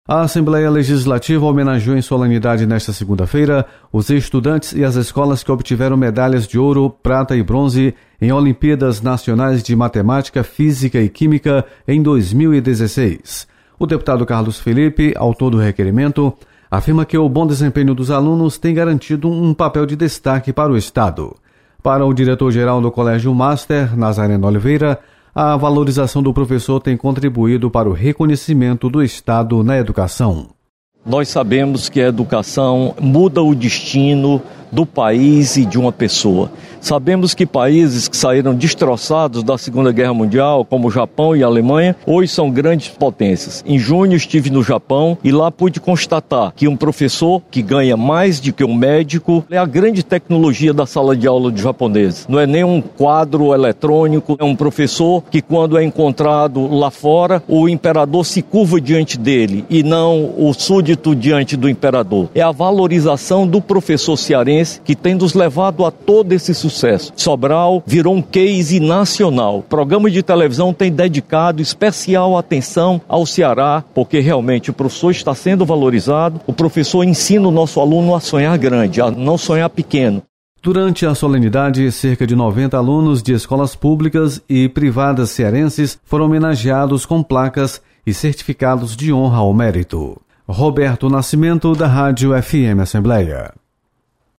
Você está aqui: Início Comunicação Rádio FM Assembleia Notícias Solenidade